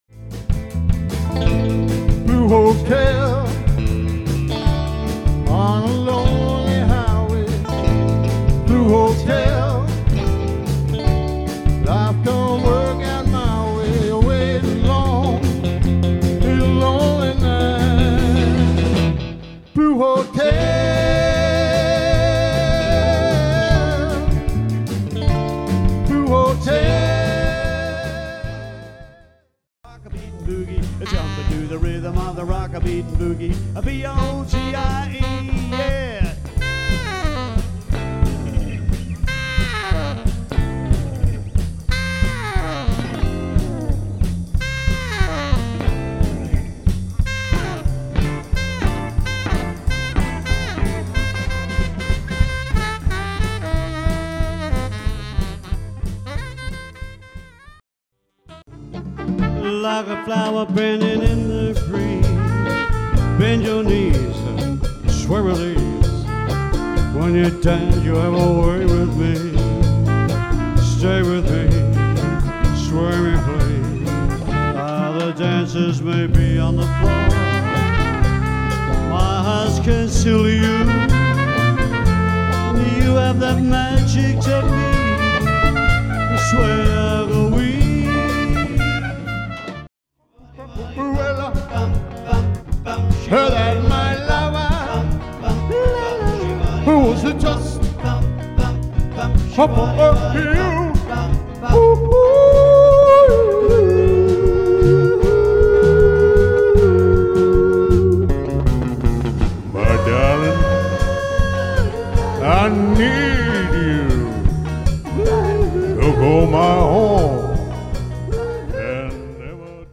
lead guitar (electric /acoustic), vocals
sax, trumpet, rhythm guitar, vocals
bass, lead guitar, vocals
drums, vocals